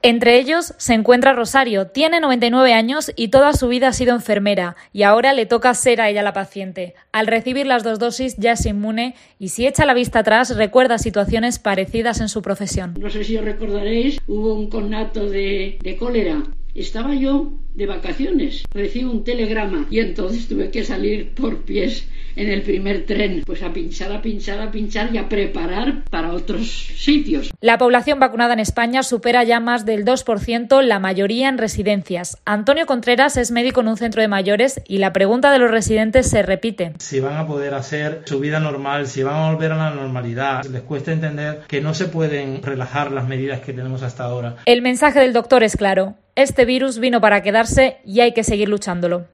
Esta longeva mujer, con animada y avivada voz, cuenta a COPE cómo su familia ha vivido otras epidemias, como la gripe española de 1918, “yo todavía no había nacido, pero si hubiese habido una vacuna como ahora, mis hermanos no habrían muerto, seríamos 6 en lugar de 4 como fuimos”.